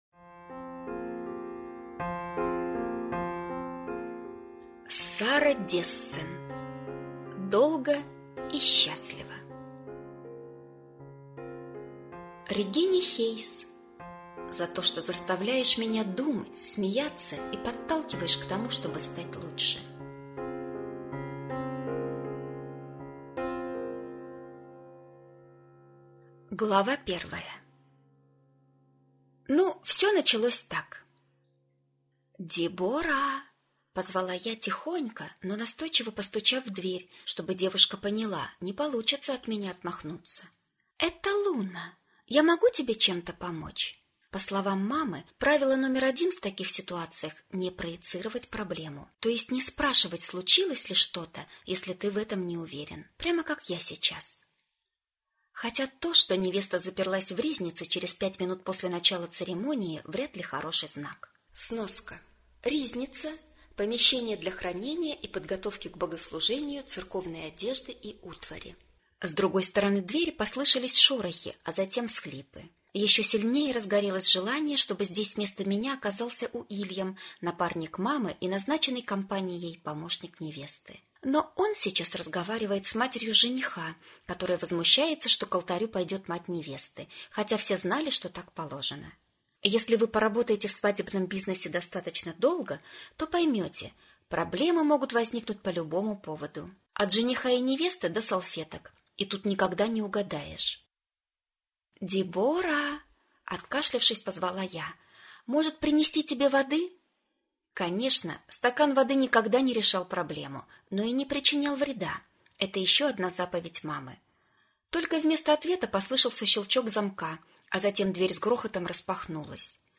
Аудиокнига Долго и счастливо | Библиотека аудиокниг